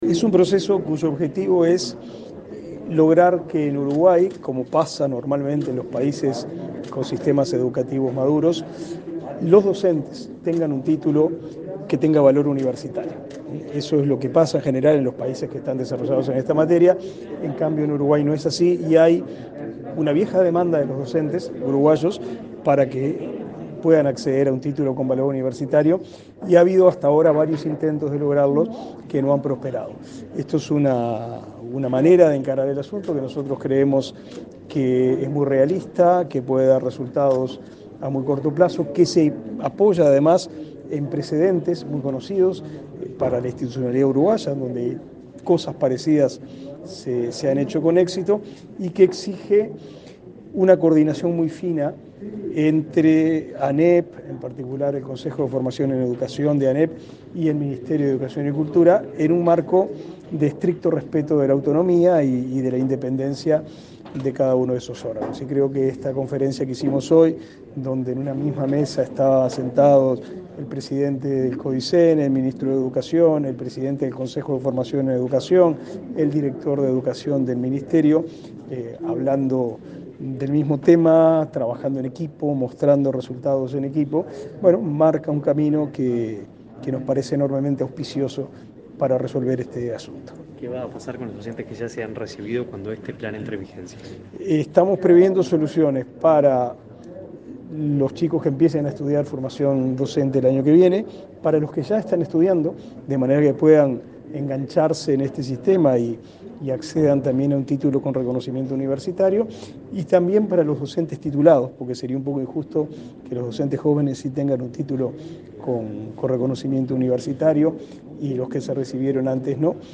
Declaraciones a la prensa del ministro Pablo da Silveira